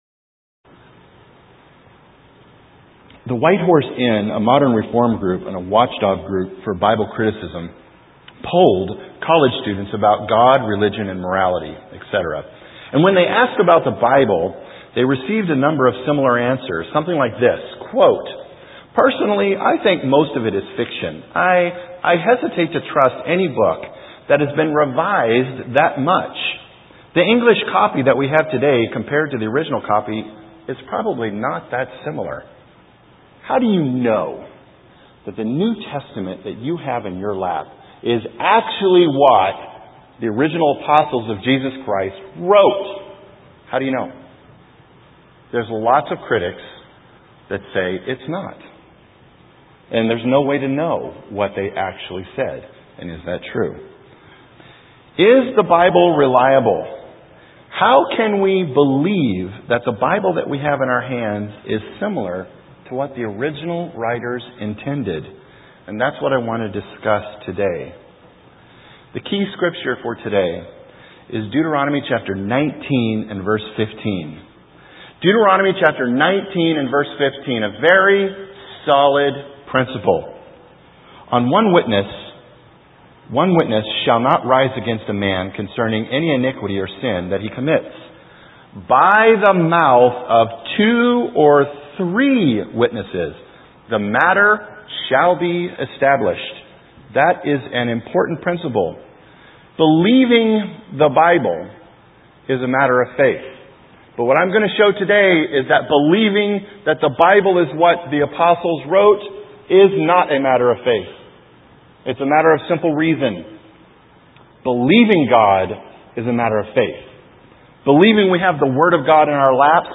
This sermon helps us come to understand how we can trust the text of the bible.